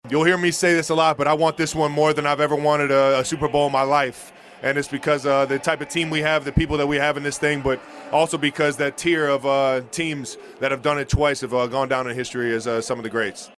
Players spoke to the media at Super Bowl 58 Opening Night from Allegiant Stadium on Monday.